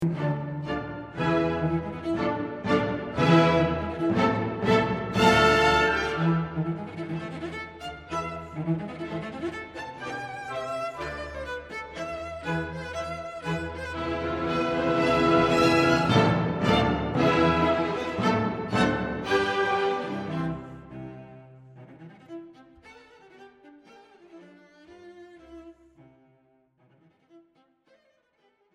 Robert Schumann: Concerto for Cello and Orchestra in A minor, Op. 129